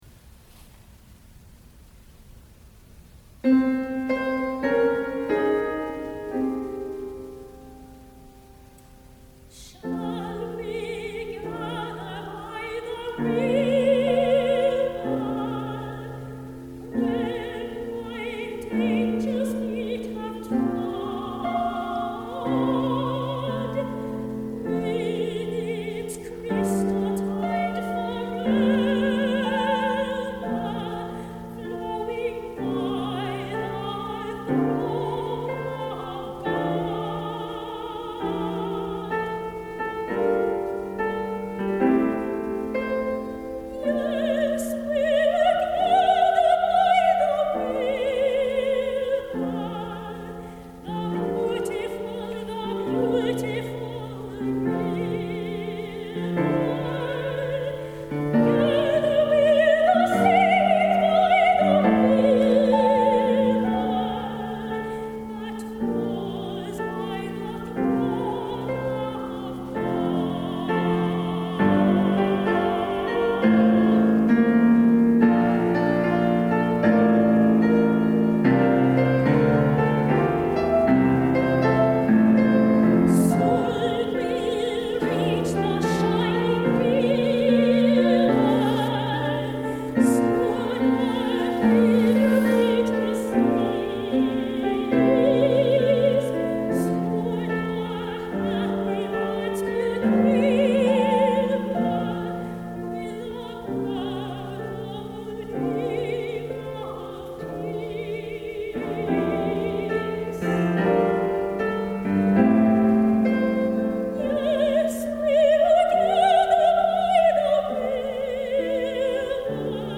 piano
*live performances